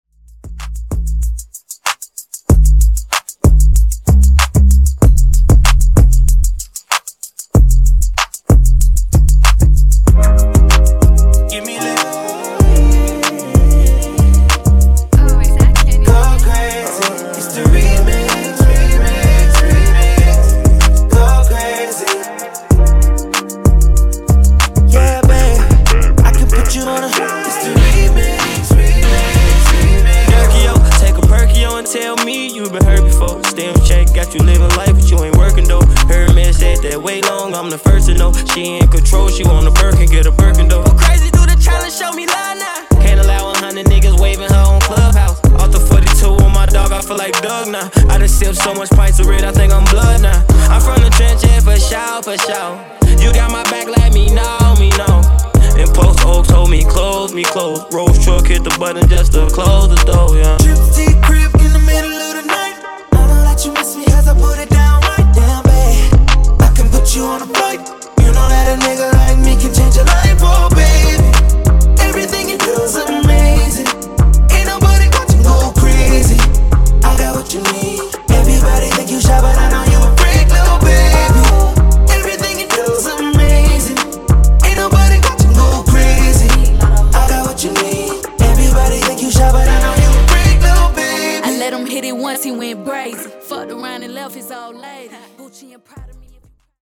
Genres: R & B , RE-DRUM Version: Clean BPM: 94 Time